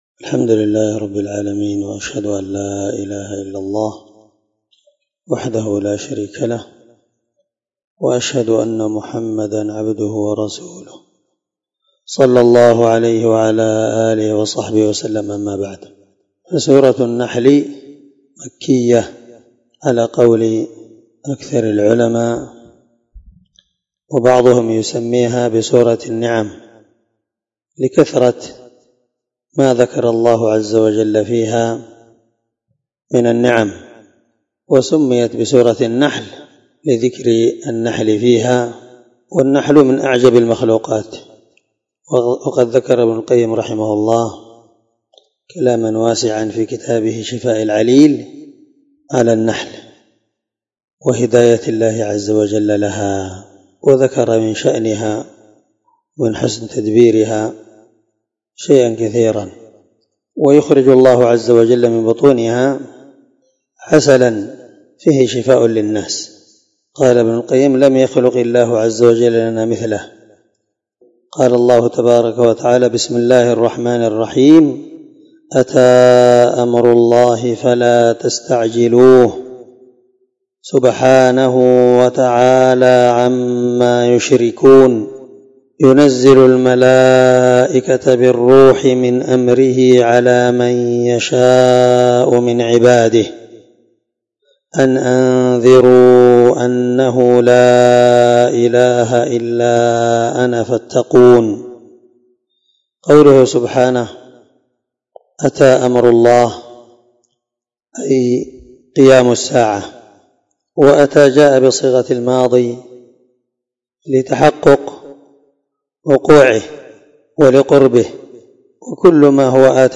الدرس 1 تفسير آية (1-2) من سورة النحل
16سورة النحل مع قراءة لتفسير السعدي